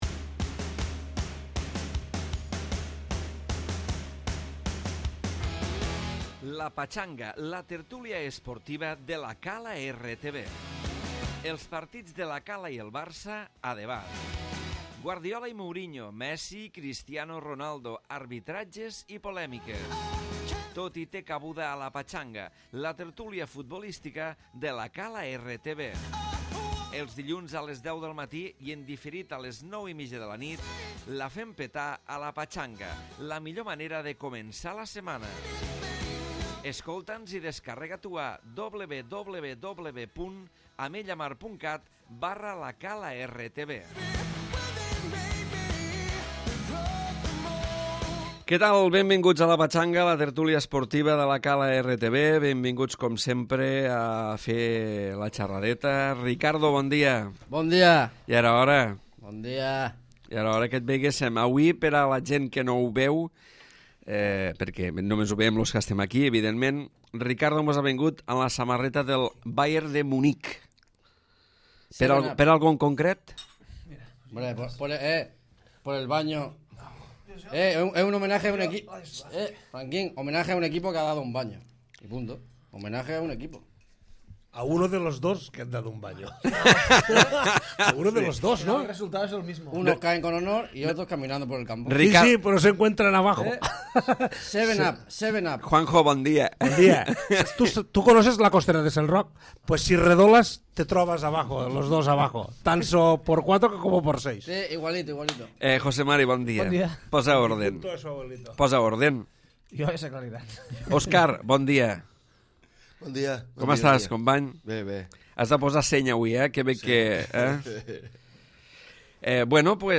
Tertúlia habitual amb especial atenció a la vivtòria del primer equip de La Cala davant del Campredó i a les eliminacions de Barça i Madrid a la Champions League.